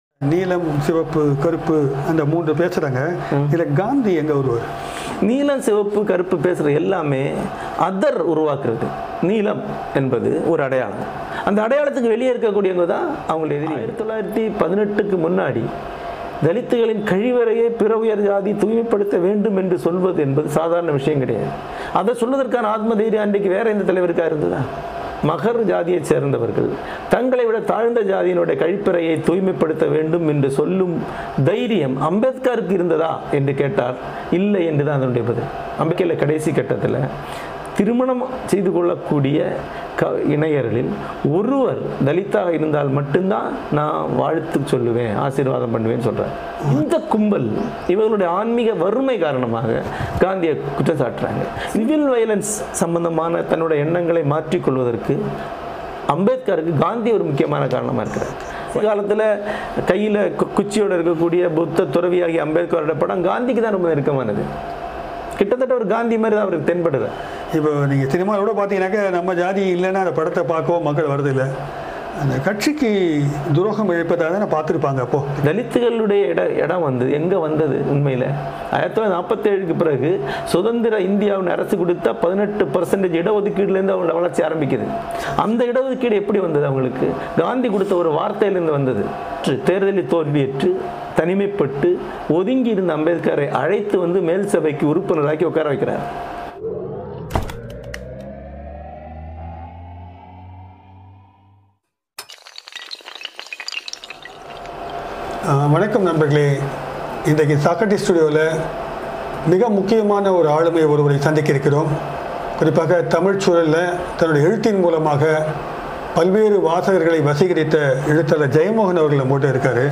தலததகளகக-எதரனவர-கநத-ll-எழததளர-ஜயமகனடன-சநதபப-ll-Is-Gandhiji-against-Dalits.mp3